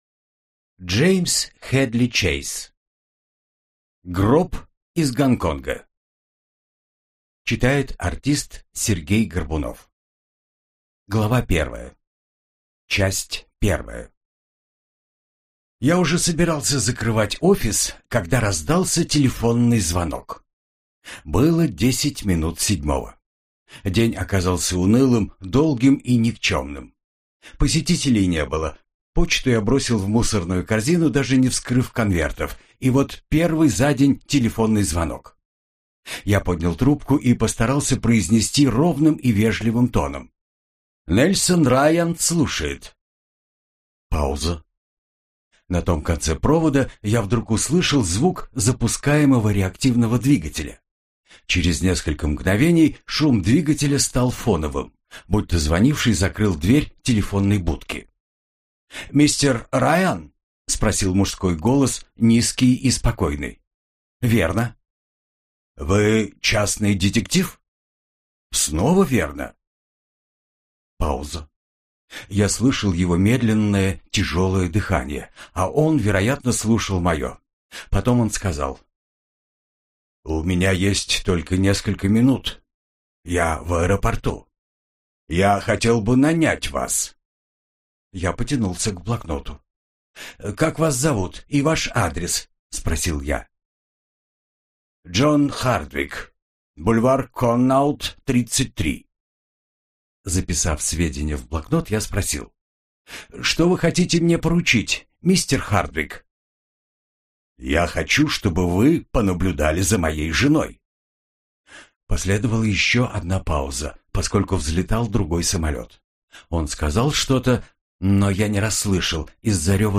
Аудиокнига Гроб из Гонконга | Библиотека аудиокниг